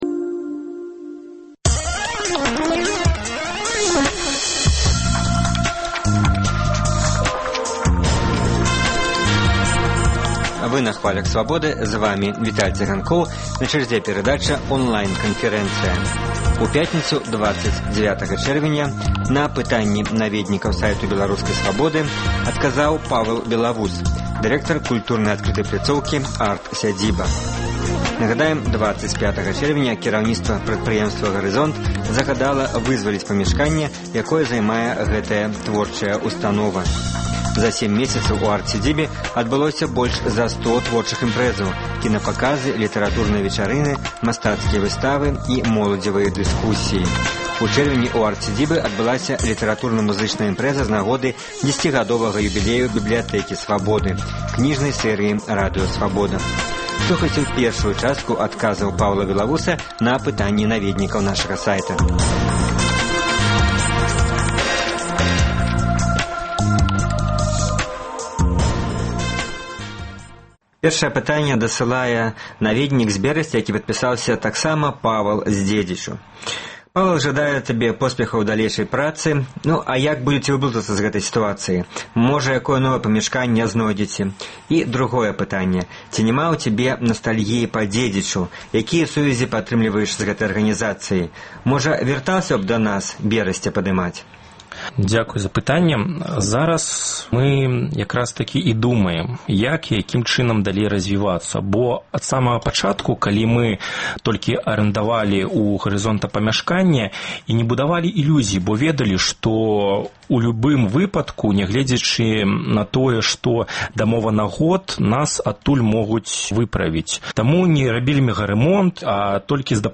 Онлайн-канфэрэнцыя